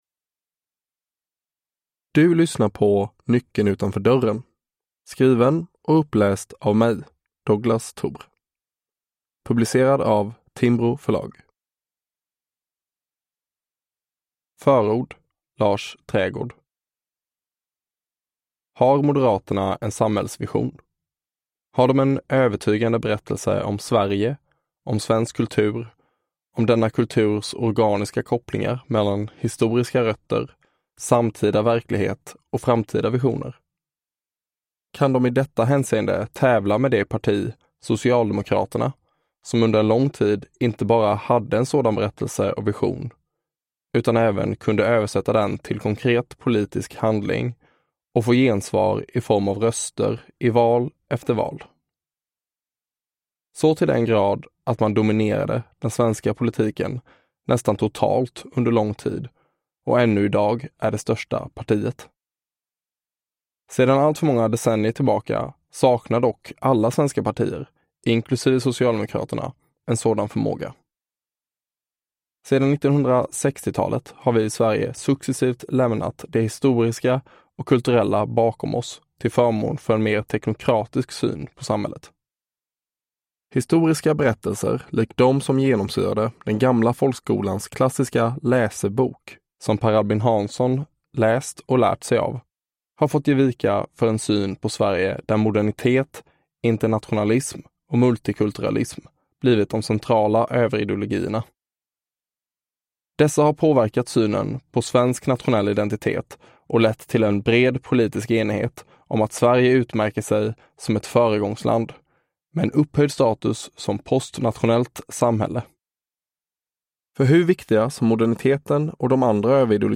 Nyckeln utanför dörren – Ljudbok